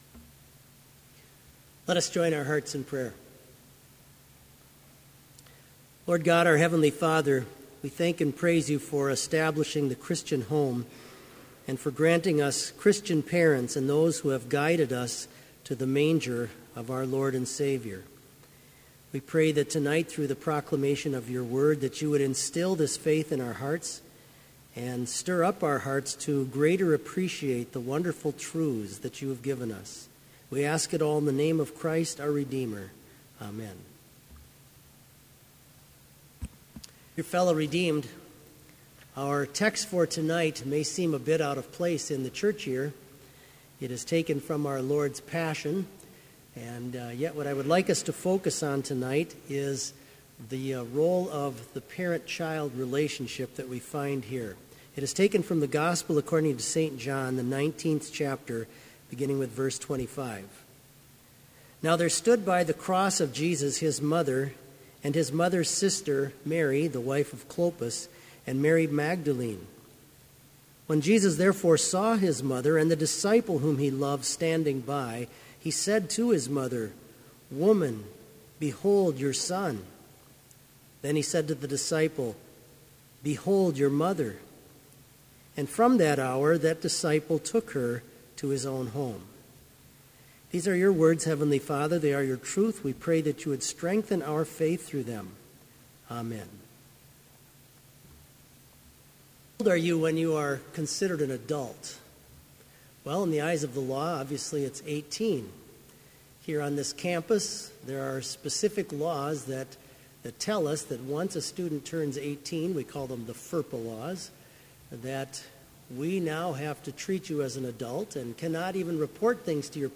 Sermon audio for Evening Vespers - January 13, 2016